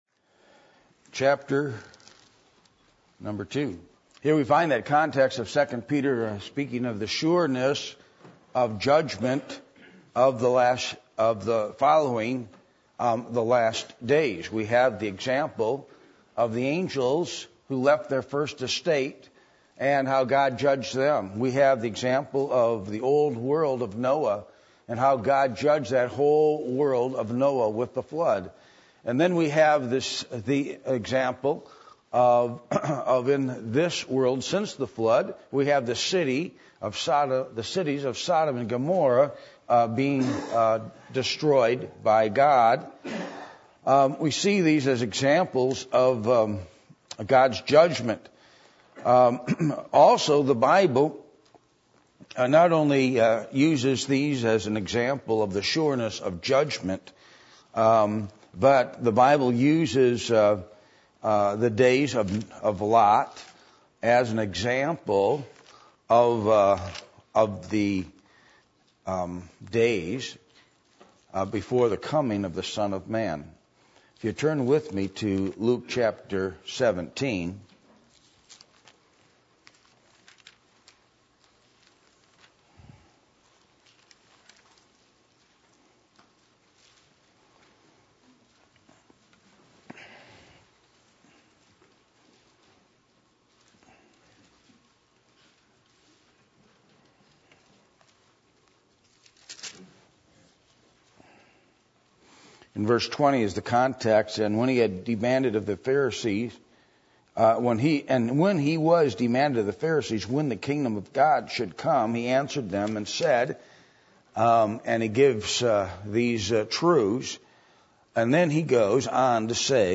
Luke 17:26-30 Service Type: Sunday Evening %todo_render% « Faith